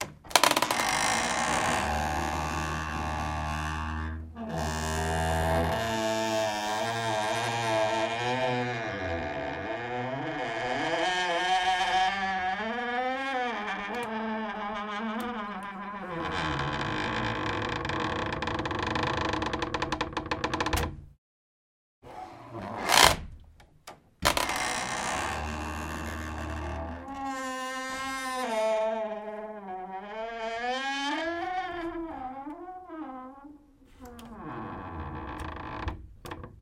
自然 " 利瓦森林中的吱吱作响的树
Tag: 嘎嘎作响